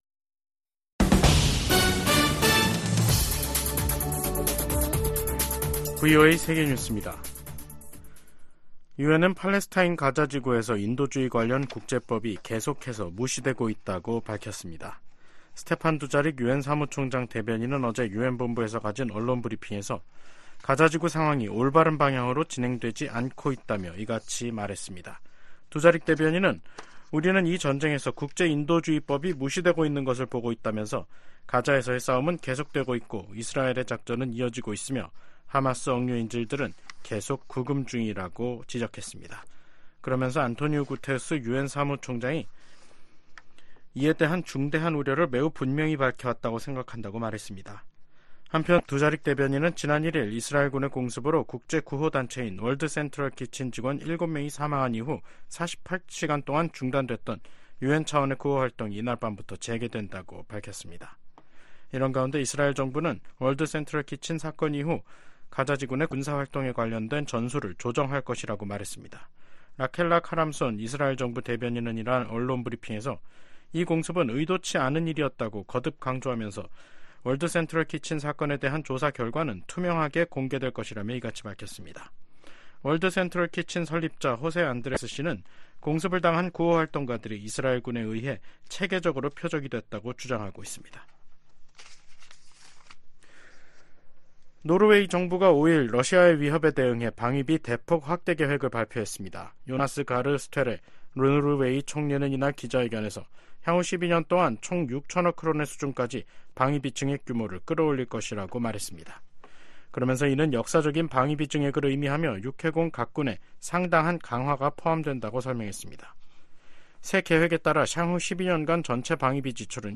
VOA 한국어 간판 뉴스 프로그램 '뉴스 투데이', 2024년 4월 5일 3부 방송입니다. 유엔 인권이사회가 올해 20번째로 북한의 심각한 인권 상황을 규탄하는 북한인권결의안을 채택했습니다. 한국과 중국, 일본이 4년여 간 중단됐던 3국 정상회의를 다음달 중 서울에서 개최하는 방안을 협의 중입니다. 유엔 안보리에서 미한일 3국 대표가 북한의 악의적인 사이버 공격에 대한 우려를 표명했습니다.